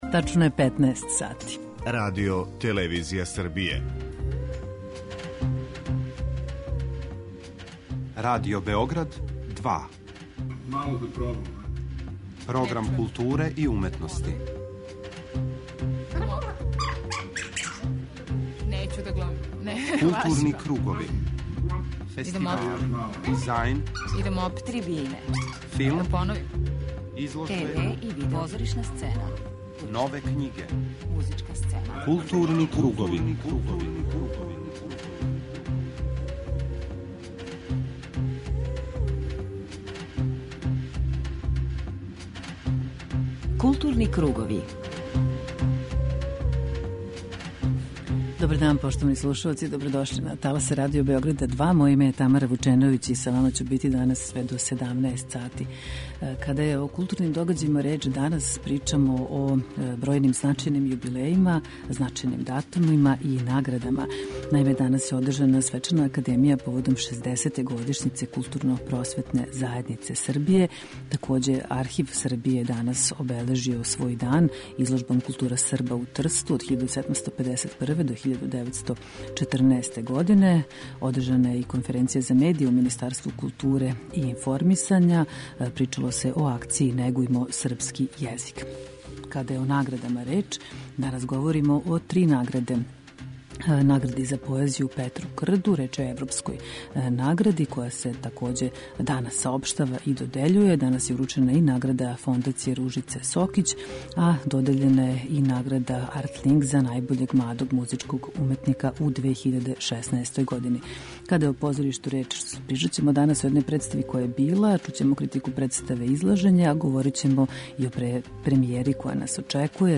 У првом, магазинском делу емисије информисаћемо вас о актуелним културним догађајима, а у темату 'Златни пресек' разговарамо о две теме.